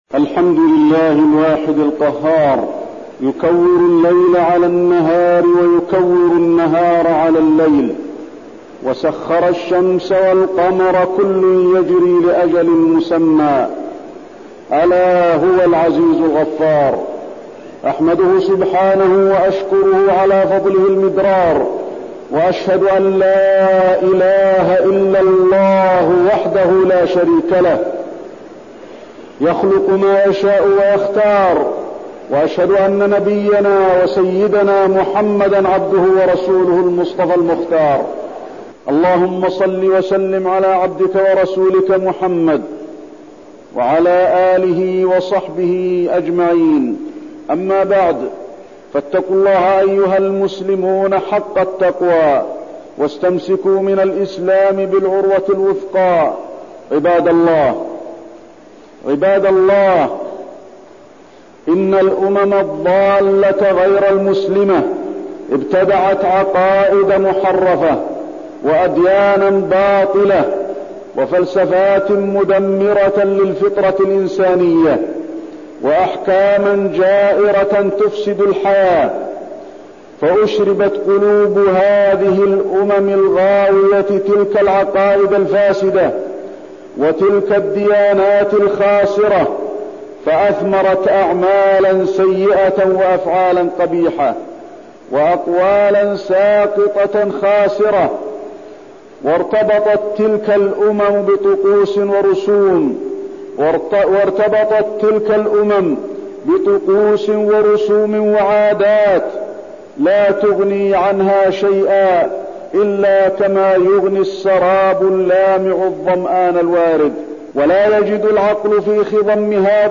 تاريخ النشر ٢٠ ذو القعدة ١٤٠٩ هـ المكان: المسجد النبوي الشيخ: فضيلة الشيخ د. علي بن عبدالرحمن الحذيفي فضيلة الشيخ د. علي بن عبدالرحمن الحذيفي العقائد الفاسدة